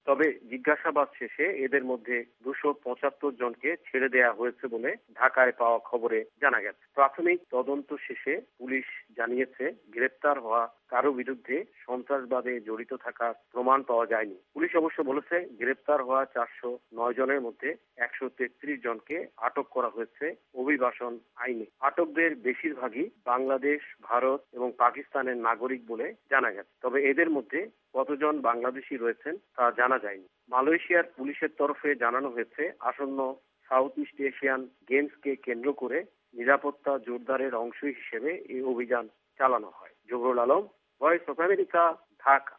ঢাকা থেকে বিস্তারিত জানিয়েছেন সংবাদদাতা